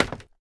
assets / minecraft / sounds / step / scaffold2.ogg
scaffold2.ogg